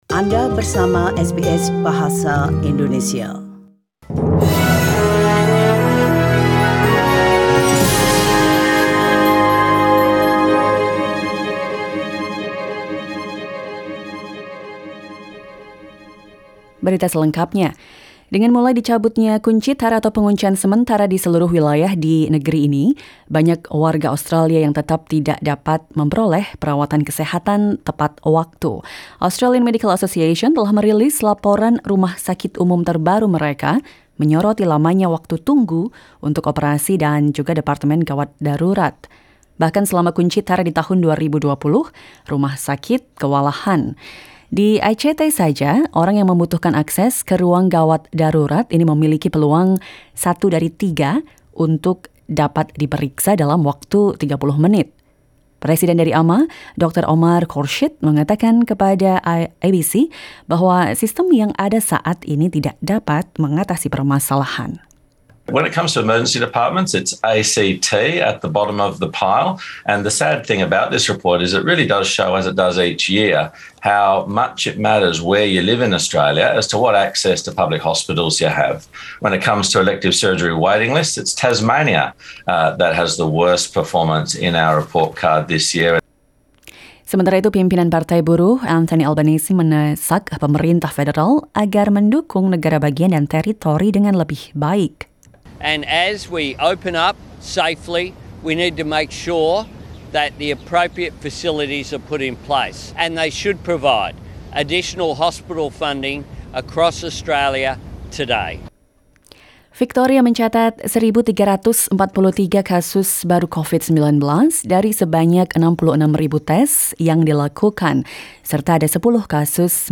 SBS Radio News in Bahasa Indonesia - 05 Nov 2021
Warta Berita Radio SBS Program Bahasa Indonesia Source: SBS